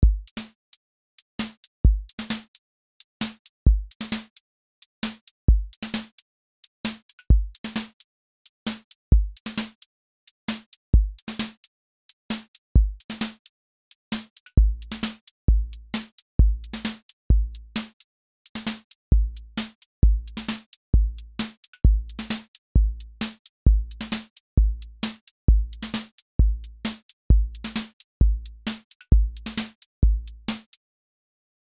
• voice_kick_808
• voice_snare_boom_bap
• voice_hat_trap
• voice_sub_pulse
• tone_warm_body
• fx_drum_bus_glue